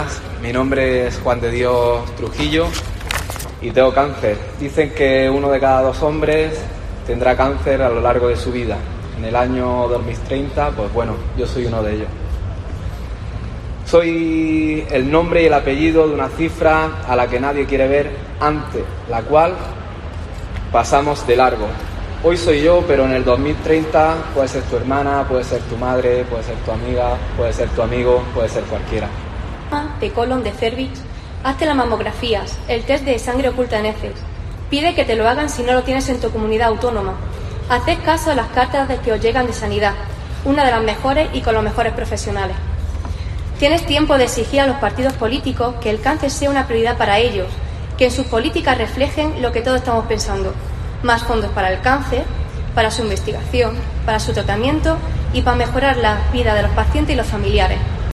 Enfermos de cáncer leen un manifiesto en el Ayuntamiento